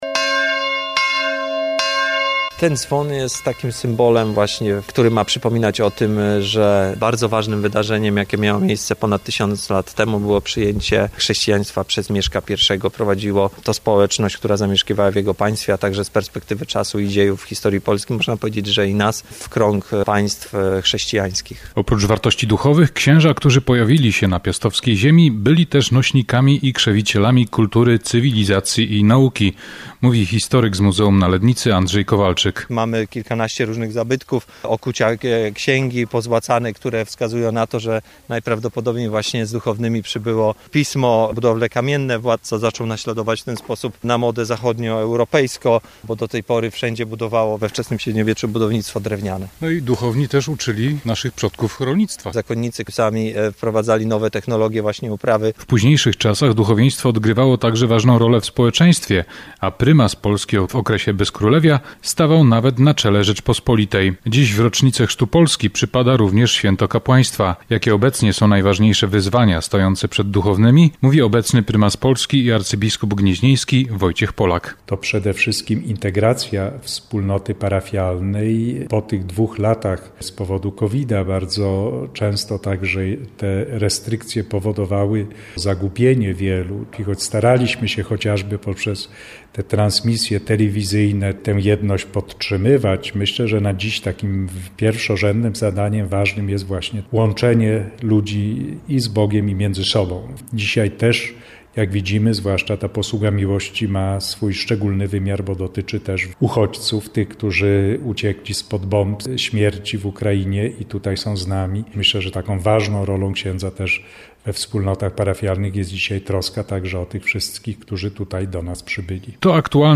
Specjalnie z tej okazji na Ostrowie Lednickim, przy najlepiej zachowanych w Polsce ruinach pałacu pierwszego władcy odezwał się dzwon zwany " Mieszko i Dobrawa".